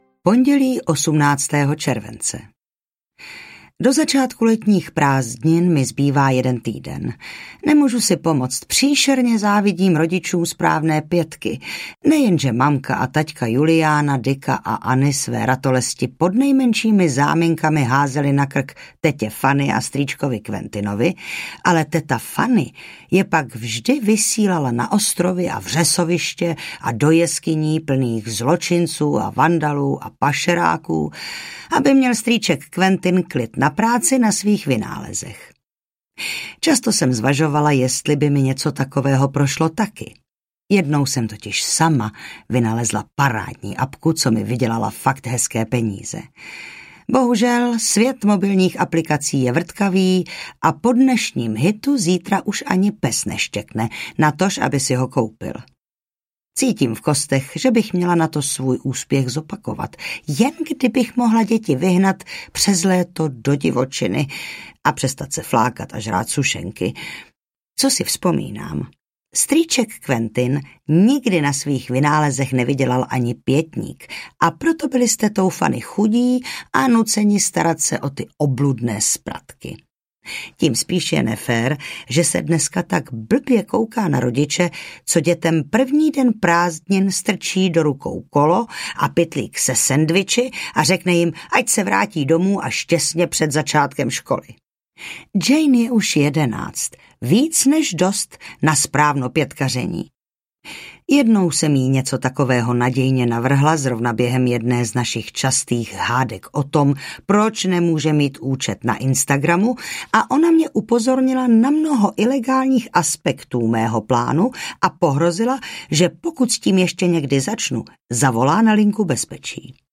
Proč máma nadává audiokniha
Ukázka z knihy